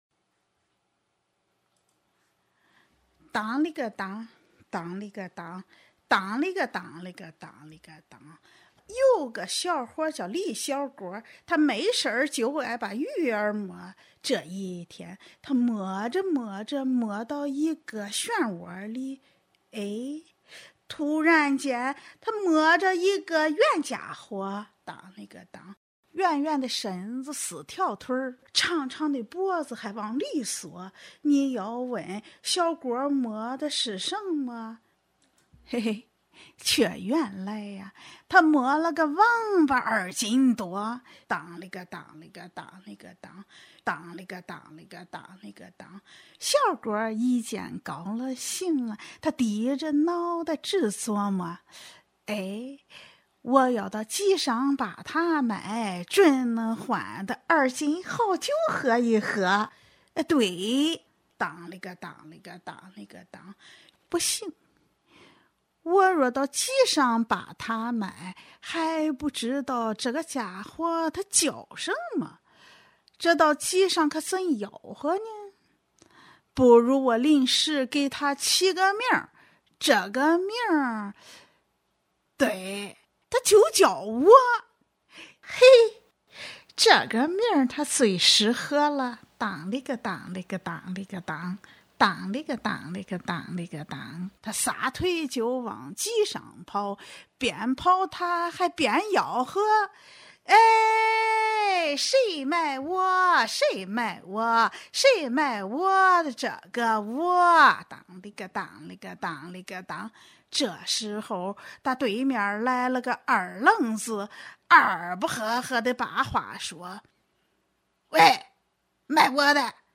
山东快书《原来王八就是我